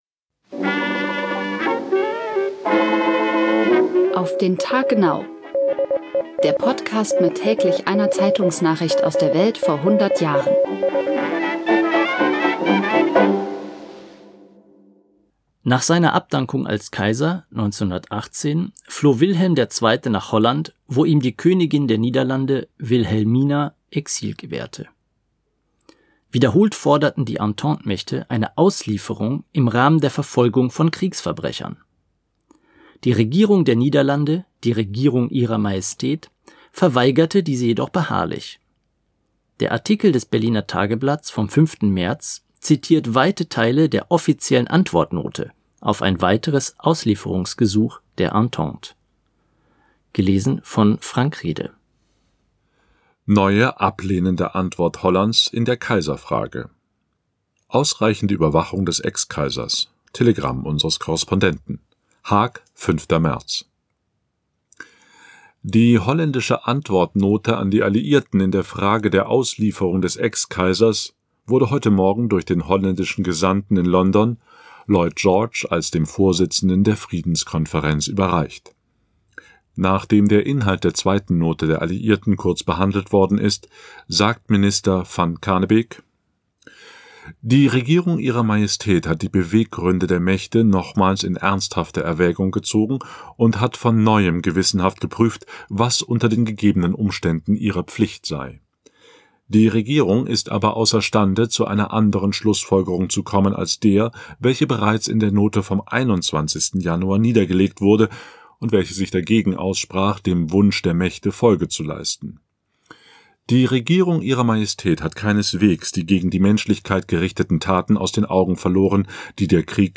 Der Podcast mit täglich einer Zeitungsnachricht aus der Welt vor hundert Jahren